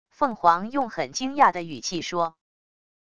凤凰用很惊讶的语气说wav音频